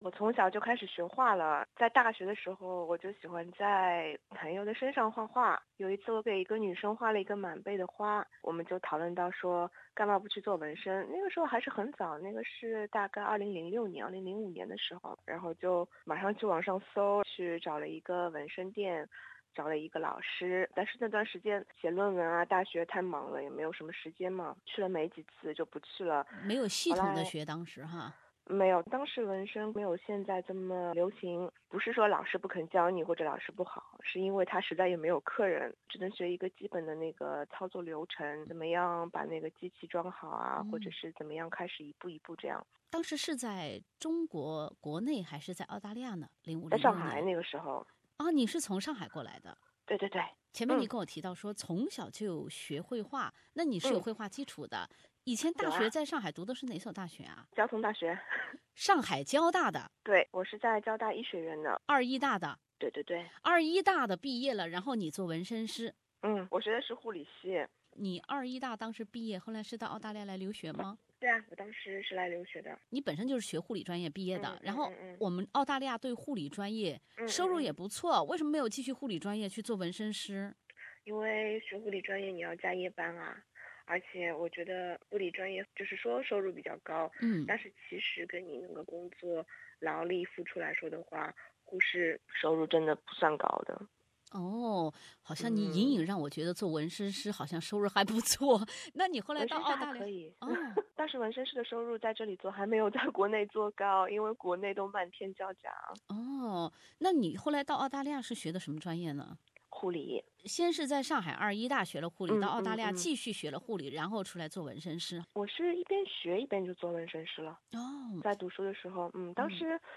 采访一开始，她就从她小时候的一份爱好聊了起来。